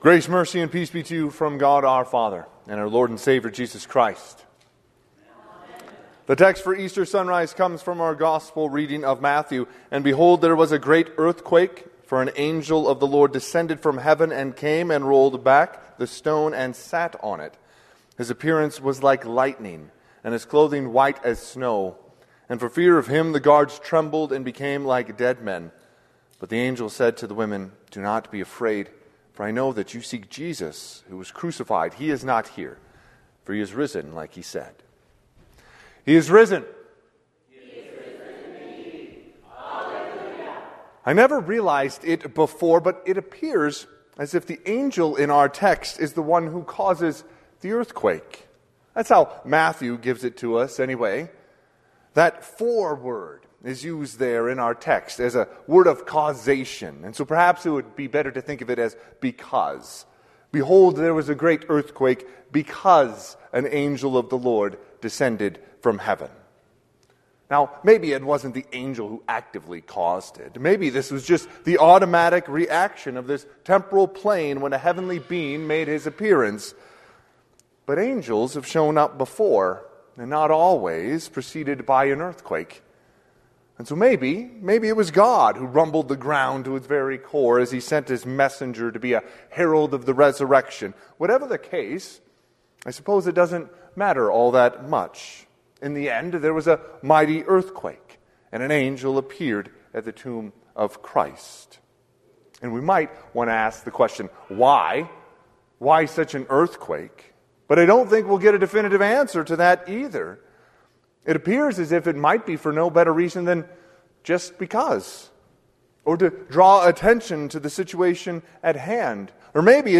Sermon - 4/20/2025 - Sunrise - Wheat Ridge Evangelical Lutheran Church, Wheat Ridge, Colorado
Easter Sunday - Sunrise Service